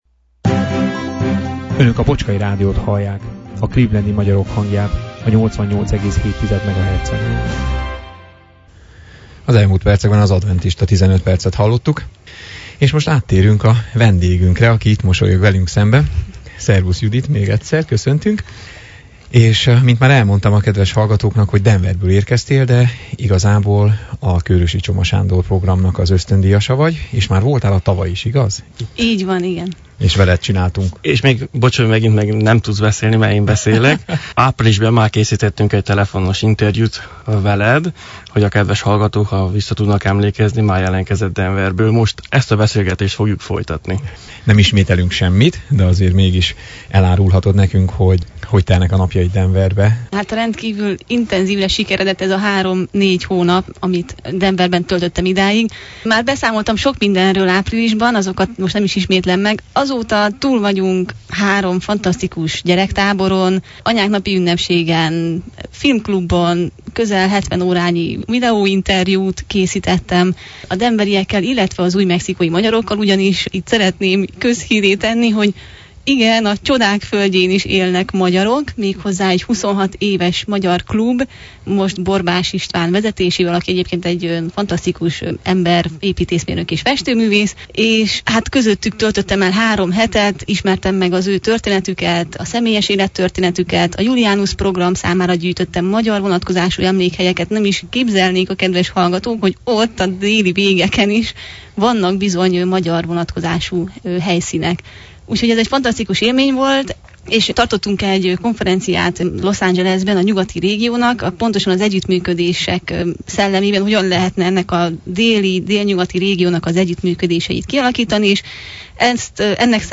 Citerajátékával is gazdagította műsorunkat.